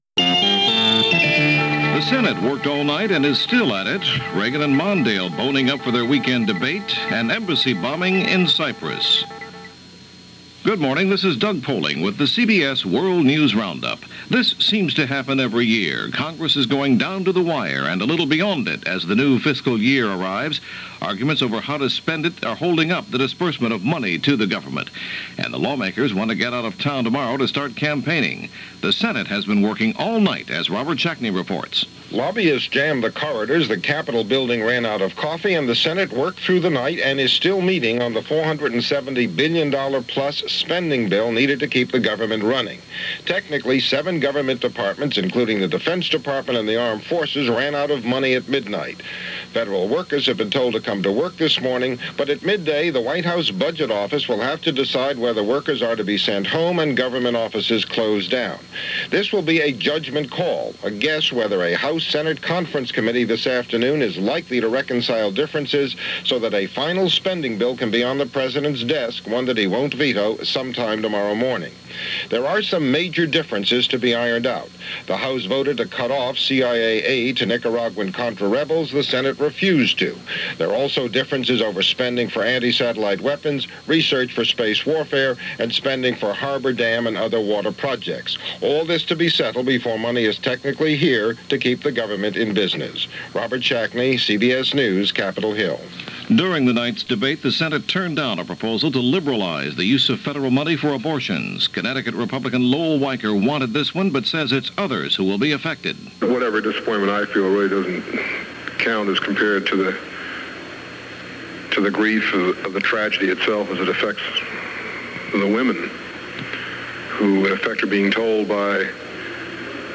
News-for-October-4-1984.mp3